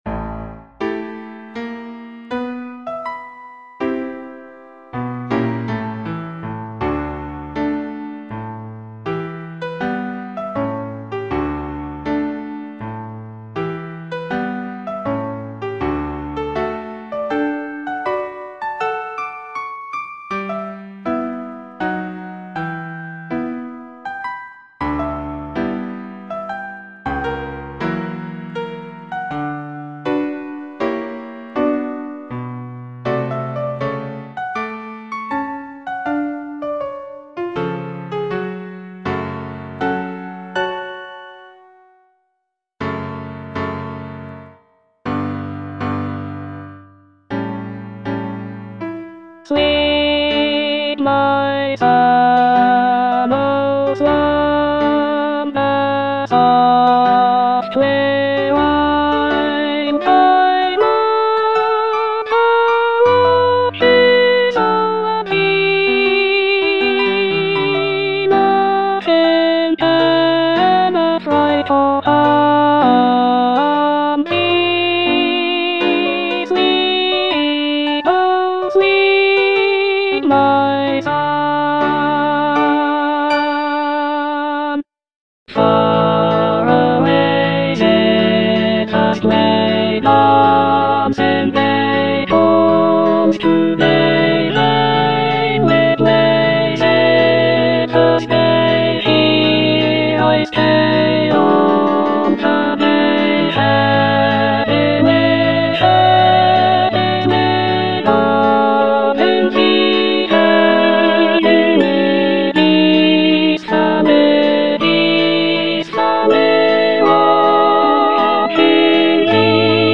(alto I) (Emphasised voice and other voices) Ads stop